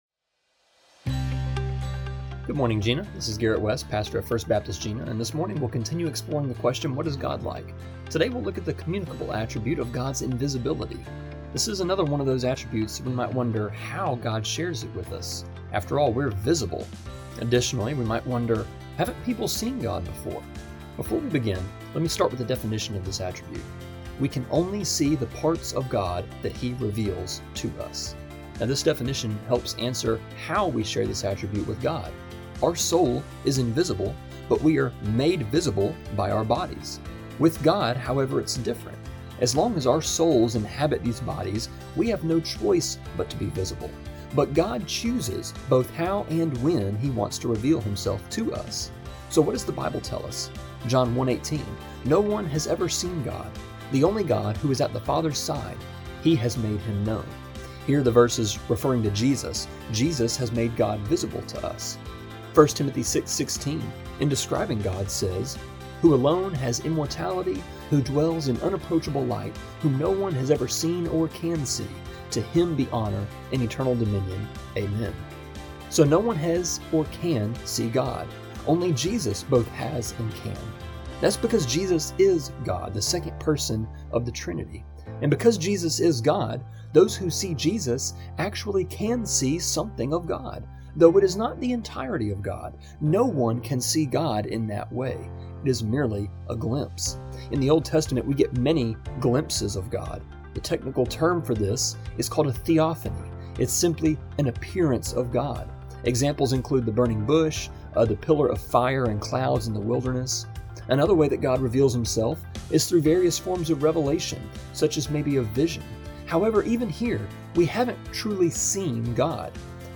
A three minute (-ish) devotion that airs Monday through Friday on KJNA just after 7am.